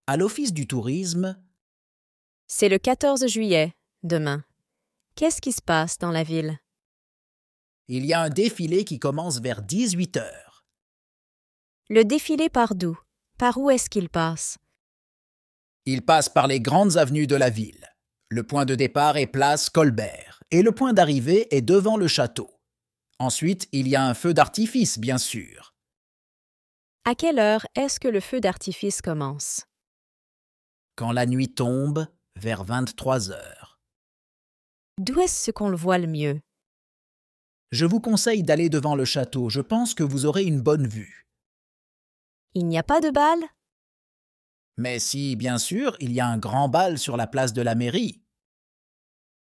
Dialogue en français – À l’office du tourisme (Niveau A2)